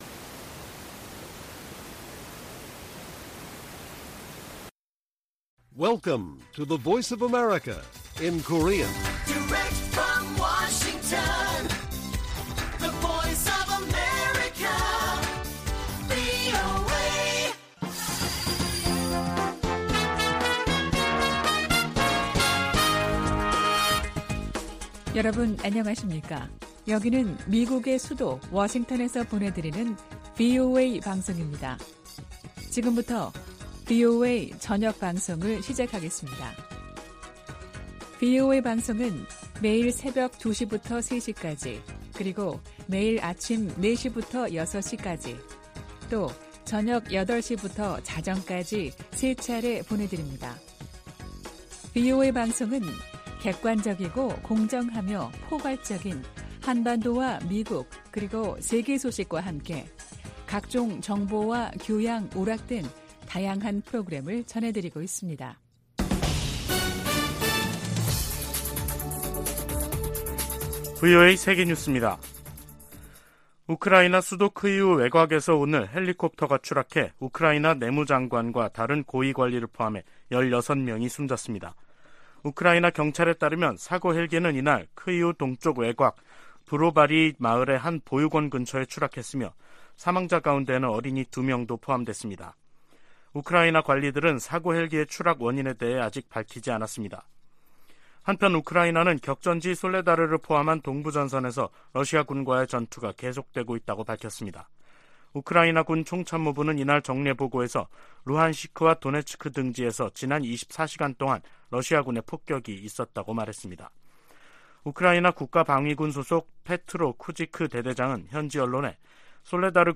VOA 한국어 간판 뉴스 프로그램 '뉴스 투데이', 2023년 1월 18일 1부 방송입니다. 미 국방부는 로이드 오스틴 국방장관이 곧 한국을 방문할 것이라고 밝혔습니다. 한국 통일부가 올해 북한과의 대화 물꼬를 트겠다는 의지를 밝히고 있으나 북한이 호응할 가능성은 크지 않다는 관측이 나오고 있습니다.